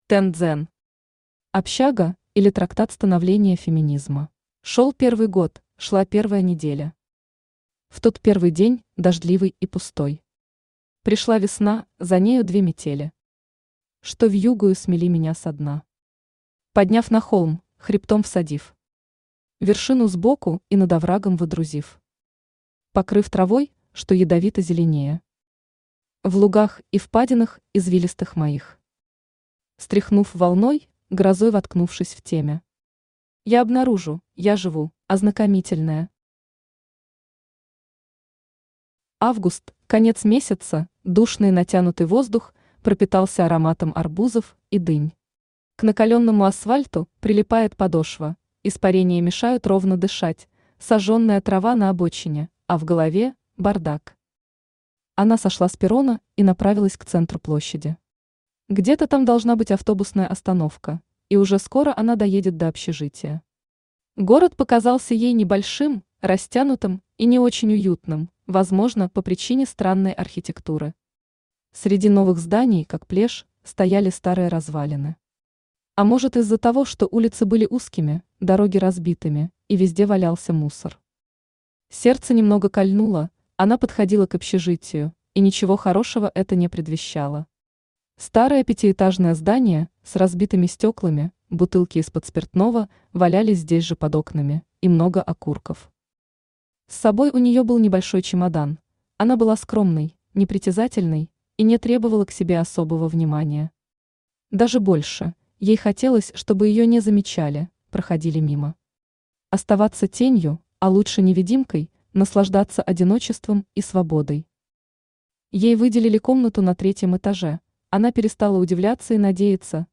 Аудиокнига Общага, или Трактат становления феминизма | Библиотека аудиокниг
Aудиокнига Общага, или Трактат становления феминизма Автор Ten Zen Читает аудиокнигу Авточтец ЛитРес.